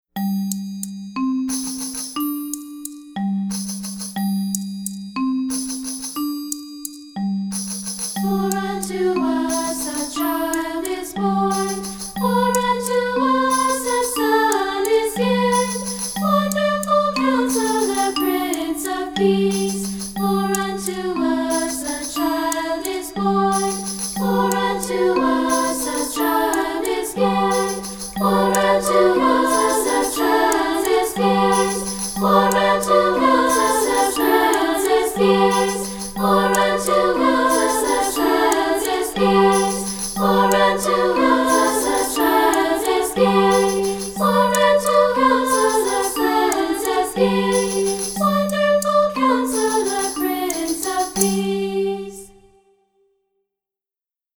Anthems for Treble Voices
Unison/two-part with Orff instruments and percussion